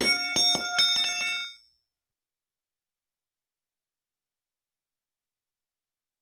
hammer.wav